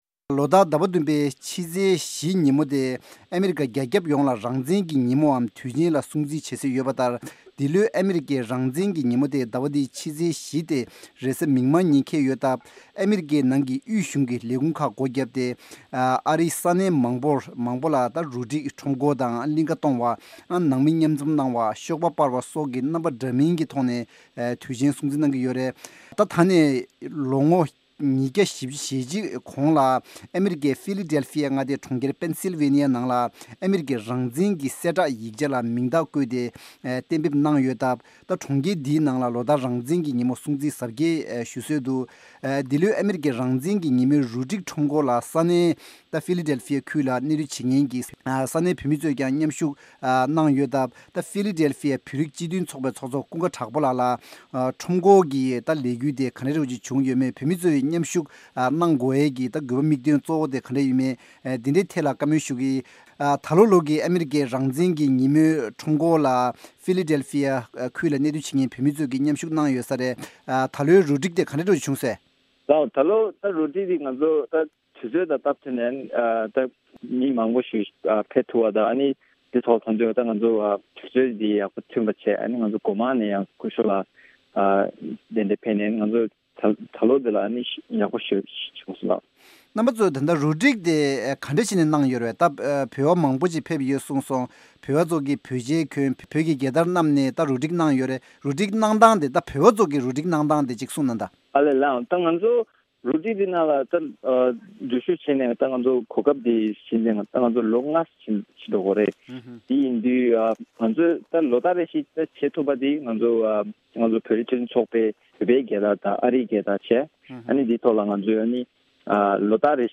འབྲེལ་ཡོད་མི་སྣར་གནས་འདྲི་ཞུས་པ་ཞིག་གསན་རོགས་གནང་།